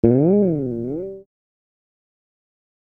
G SLIDE.wav